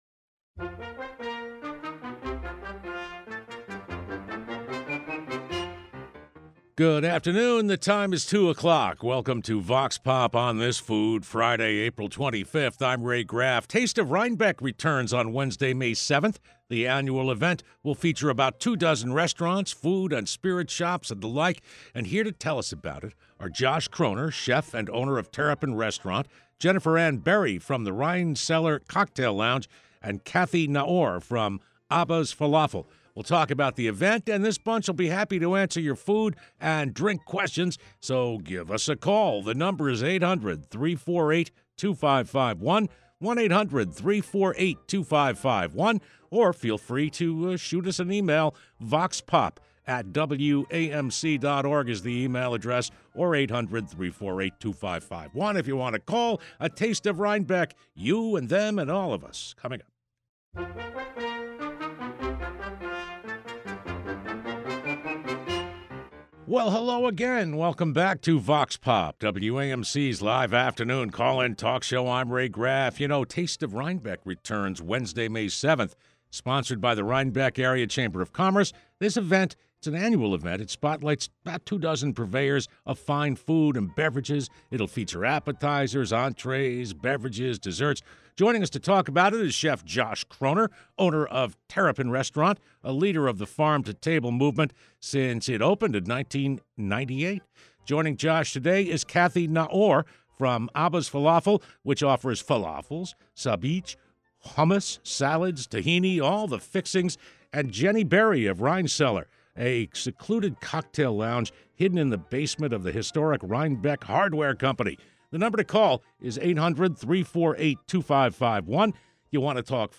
Vox Pop is WAMC's live call-in talk program.
Our experts take questions posed by WAMC listeners.